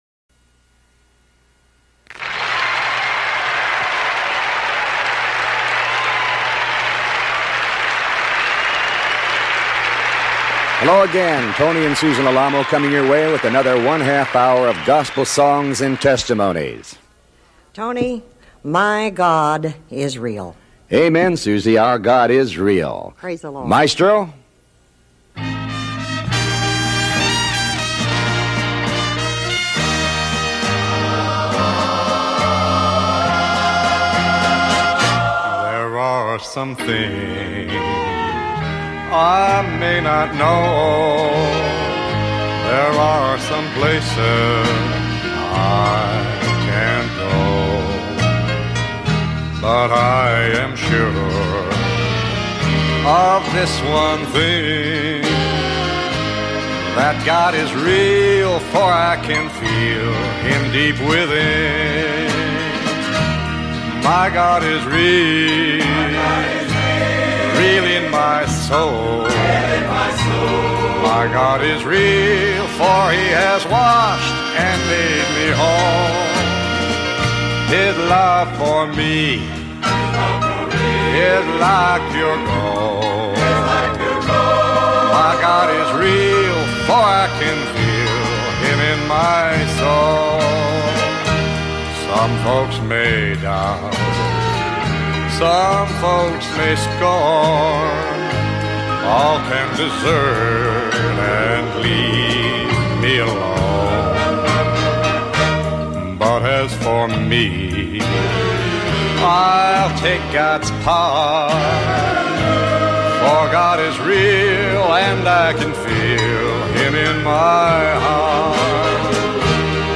Show Headline Tony Alamo Show Sub Headline Tony And Susan Program 7 Tony Alamo with Tony Alamo World Wide Ministries Tony And Susan Program 7 This program was recorded around 1974 at the Grand Ole Opry in Nashville Tennessee. Gospel songs Testimonies and a Holy Spirit filled message from Pastor Susan Alamo that you will not want to miss.